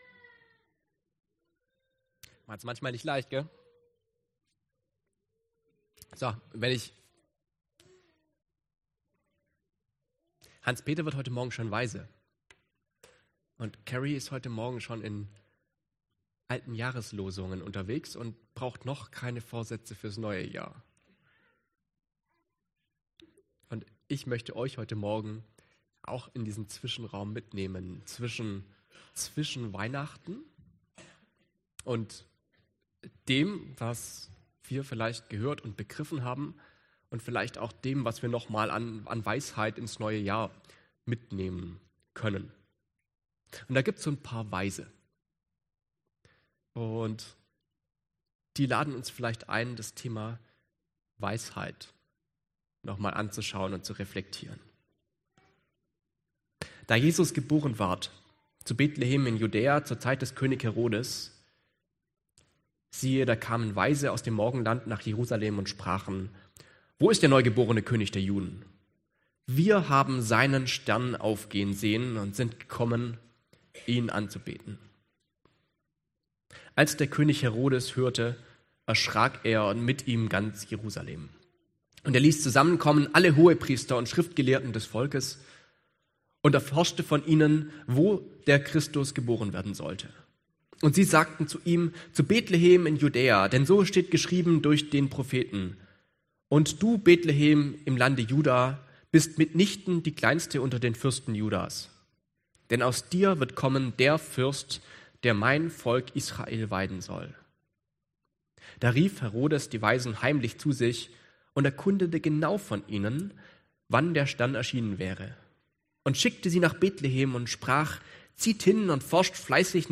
Mt Dienstart: Gottesdienst Themen: Göttliche Weisheit , Weisheit « 4.